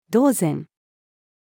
同然-female.mp3